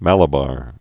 (mălə-bär)